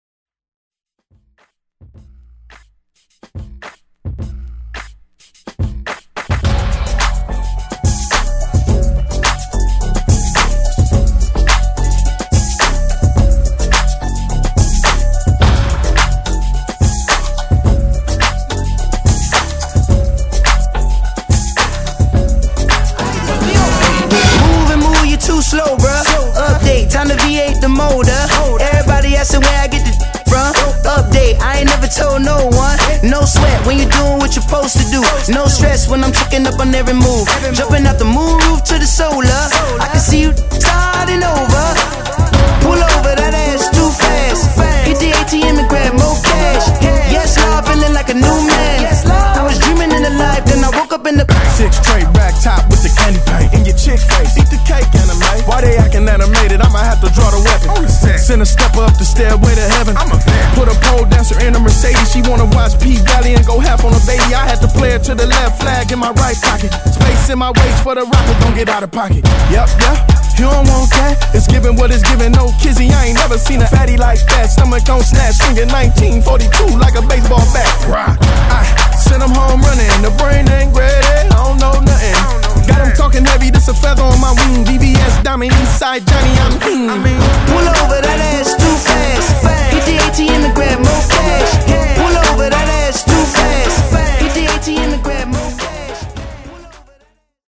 Genre: 70's
Clean BPM: 120 Time